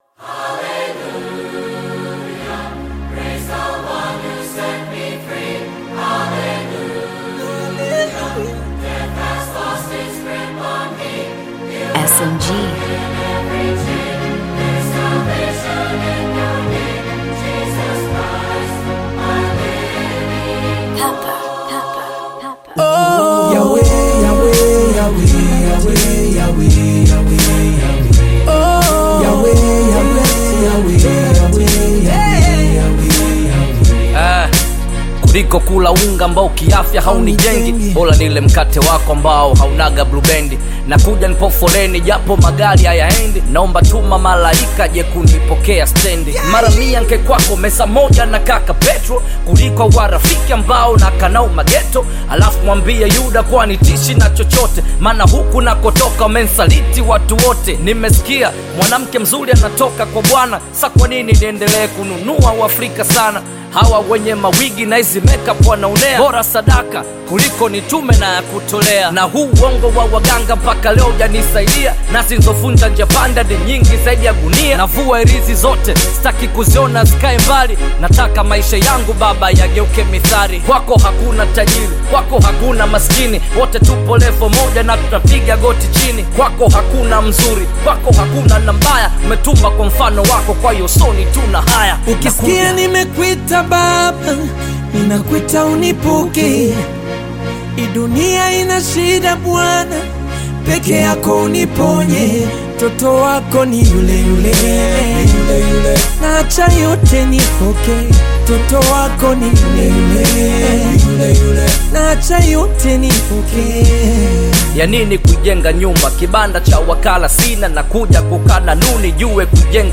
Bongo Flava Hip Hop Gospel music